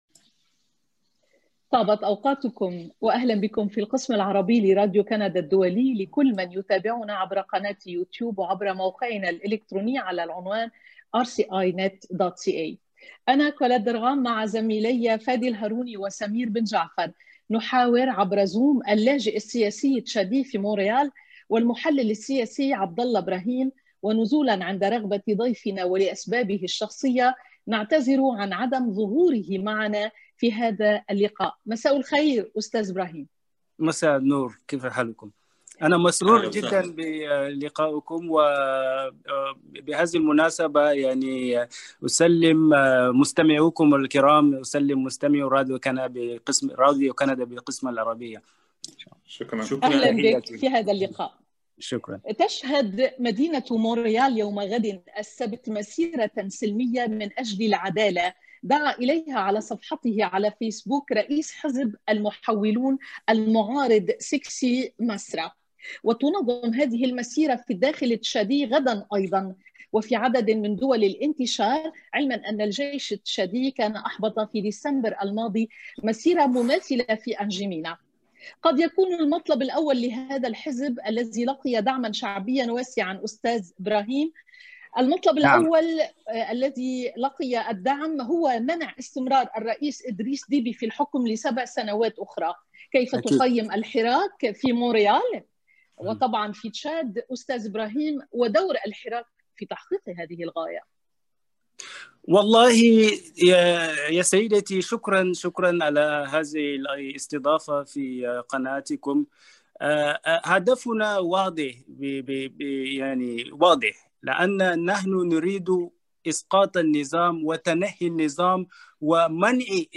نلتقي اليوم عبر تطبيق زووم أحد المشاركين بالمسيرة
يجدر التنويه بأننا، أسرة القسم العربي في راديو كندا الدولي، احترمنا رغبة الضيف بالظهور فقط بالصوت عبر زووم ولأسباب شخصية متعلقة به.